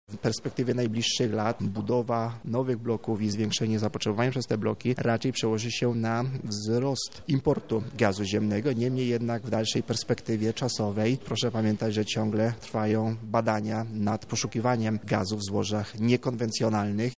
Właśnie o tym specjaliści z całego kraju dyskutują na konferencji „Gaz w Energetyce – technologie, realizacja inwestycji, finansowanie”.